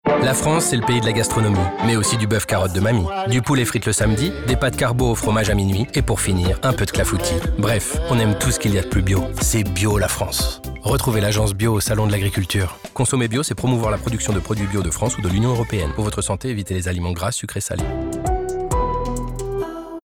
En parallèle, un nouveau spot radio a été diffusée sur les ondes à l’échelle nationale.